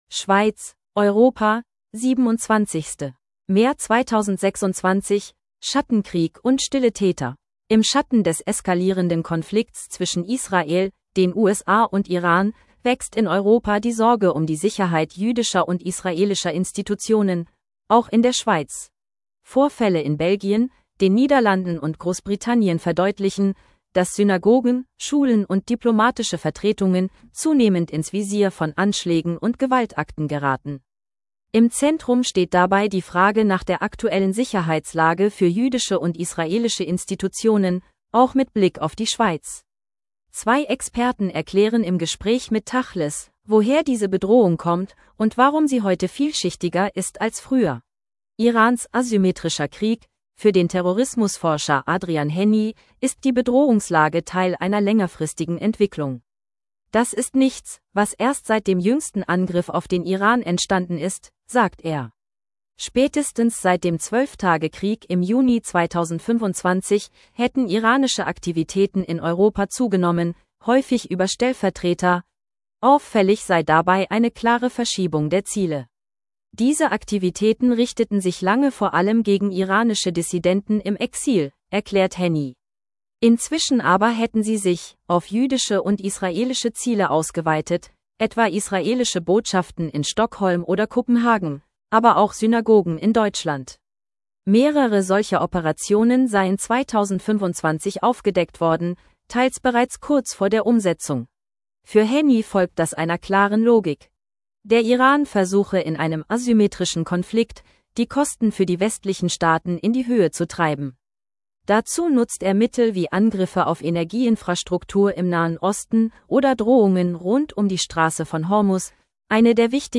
Zwei Experten erklären im Gespräch mit tachles, woher diese Bedrohung kommt und warum sie heute vielschichtiger ist als früher.